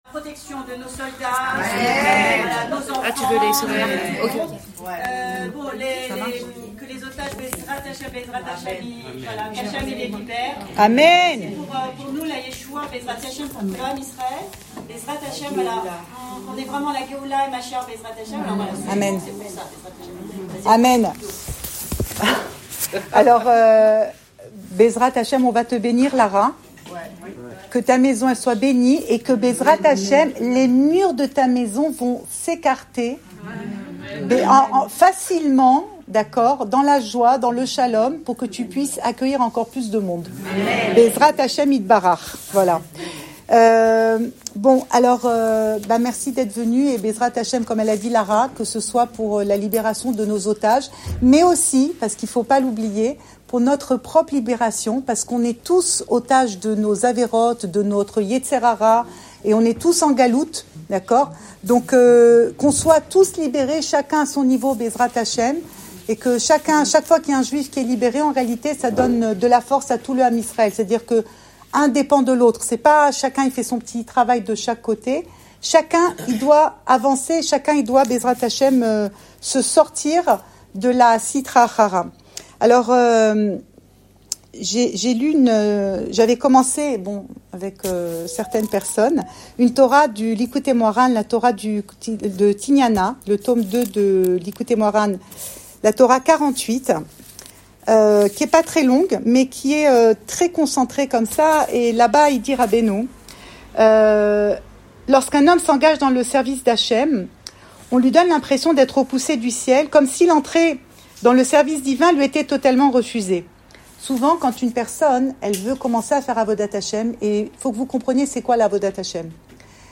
Cours audio Emouna Le coin des femmes Pensée Breslev - 4 novembre 2024 1 mai 2025 Aleph, Beth, Guimel. Enregistré à Jerusalem